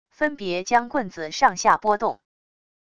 分别将棍子上下拨动wav音频